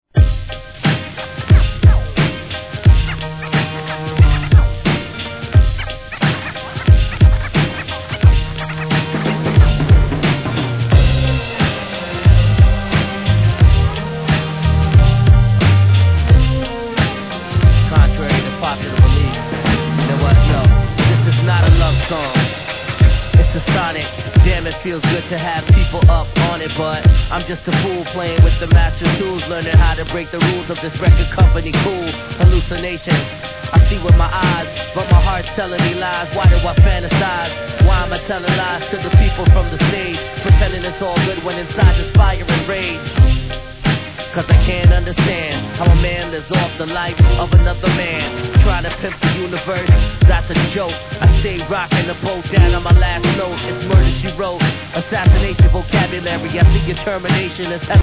HIP HOP/R&B
太いブレイクにピアノ+バイオリンの美しい旋律が合わさったクールな1曲!!